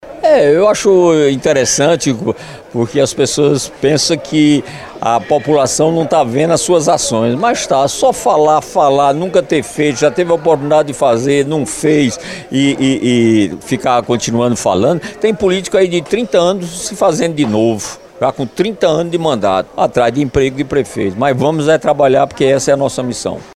Em entrevista realizada nesta quarta-feira (26), o prefeito de João Pessoa, Cícero Lucena (Progressistas), enviou uma mensagem direta ao ex-prefeito da cidade, o deputado Luciano Cartaxo (PT), que tem utilizado as redes sociais para criticar a atual administração.